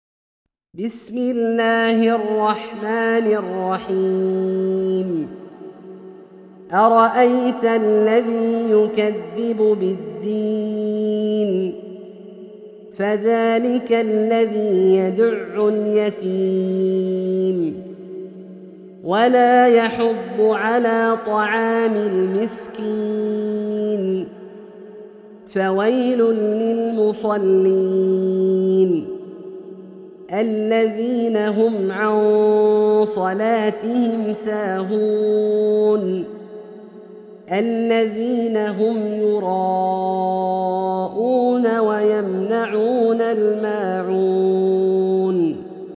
سورة الماعون - برواية الدوري عن أبي عمرو البصري > مصحف برواية الدوري عن أبي عمرو البصري > المصحف - تلاوات عبدالله الجهني